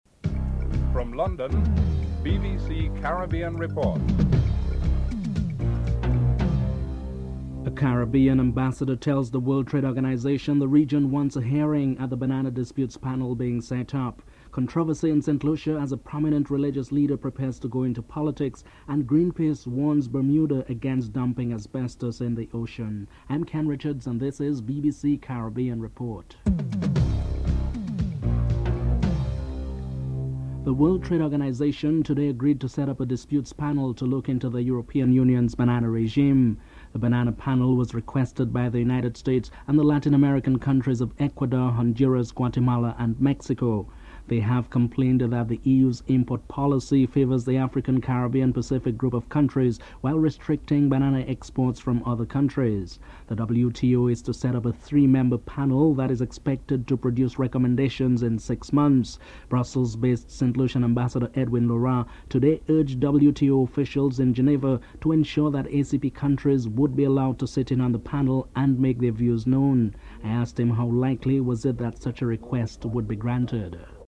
Saint Lucia's Ambassador Edwin Laurent is interviewed (00:30-03:58)
Foreign Minister Clement Rohee is interviewed (05:47-08:51)